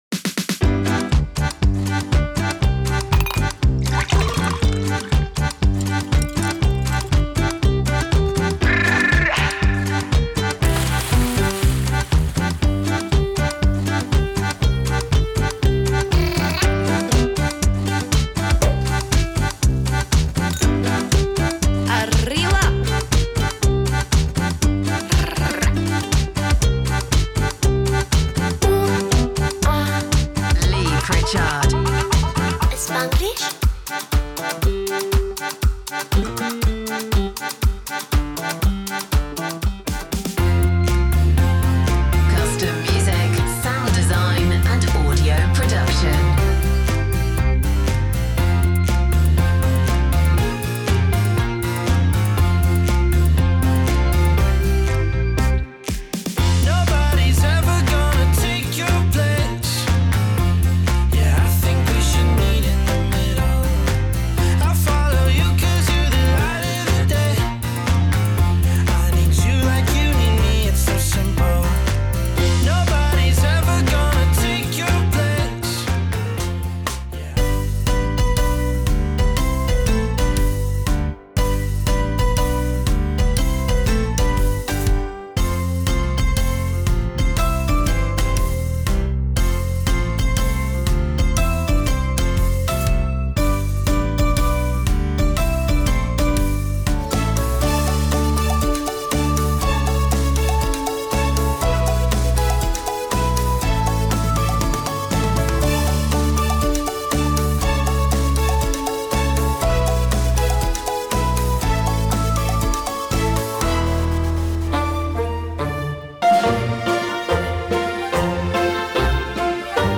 Fun & Quirky Music
these are ideal for quirky and lively productions.